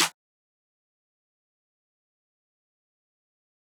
Metro Snares [Quick].wav